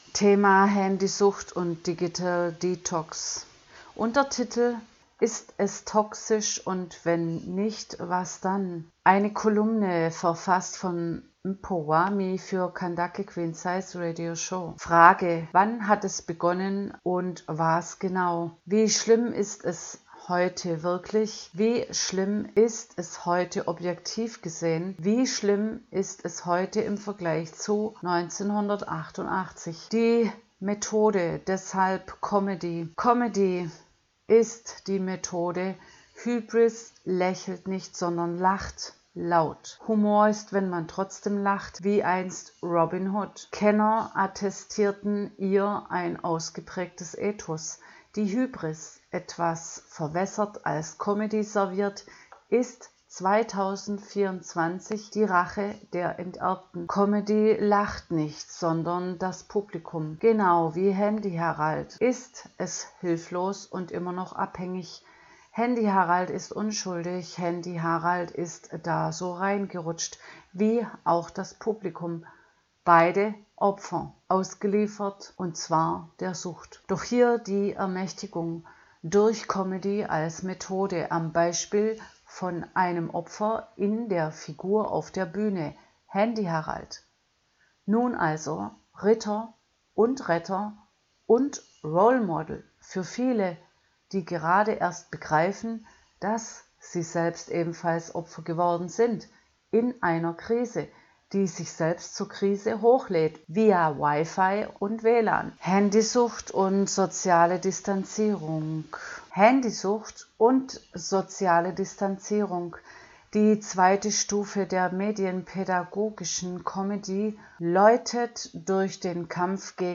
82205_Kolumne_HaendyHarald_-_eine_medienpaedagogisch_wertvolle_Comedyserie.mp3